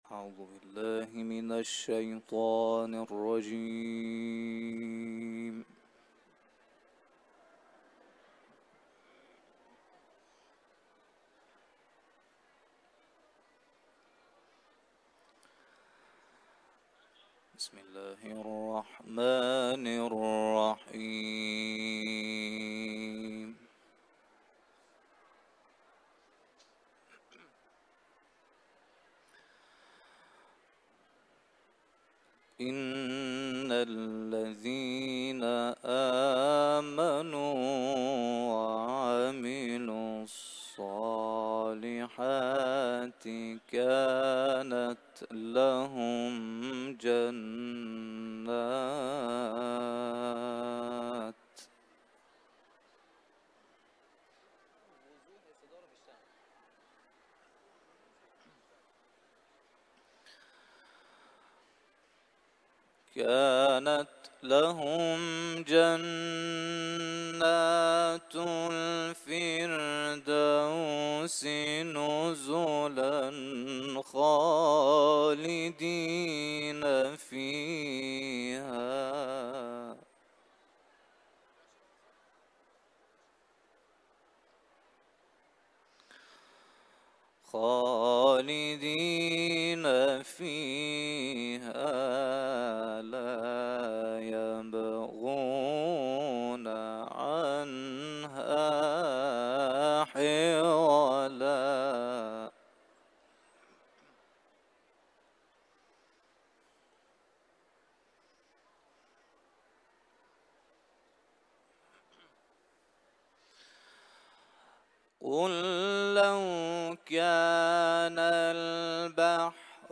Kehf suresini tilaveti